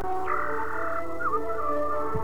Birdsong from an unidentified Biituian bird
Bird_song.ogg